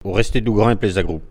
Elle provient de Saint-Gervais.
Locution ( parler, expression, langue,... )